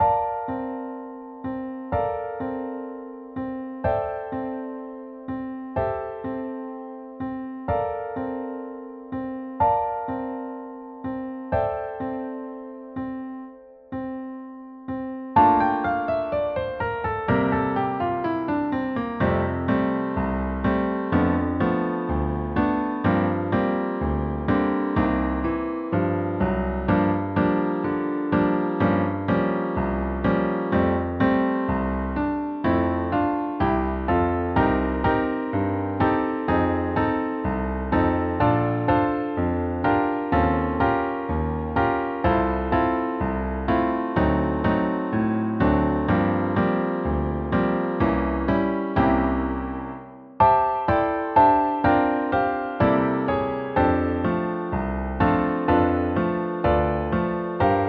Everyone Should Fall In Love at Christmas – Anna – Piano TracksDownload